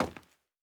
stepping sounds
PavementTiles_Mono_03.wav